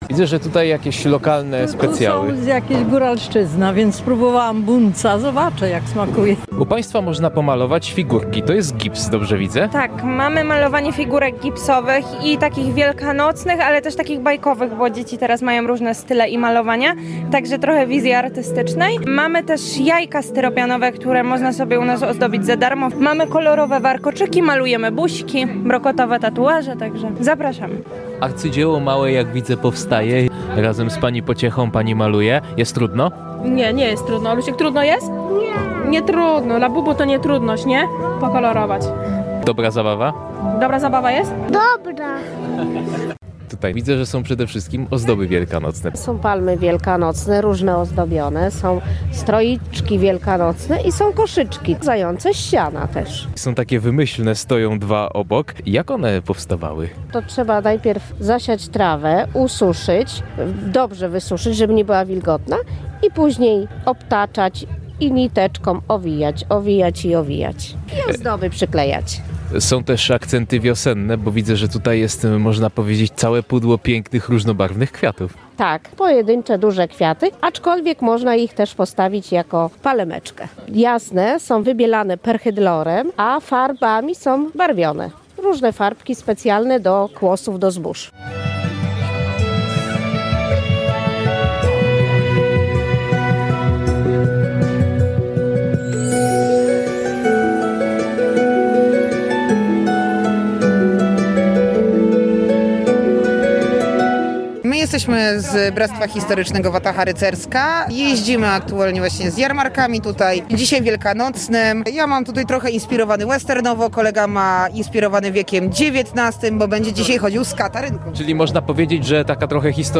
Rękodzieło, tradycja i świąteczne atrakcje • Relacje reporterskie • Polskie Radio Rzeszów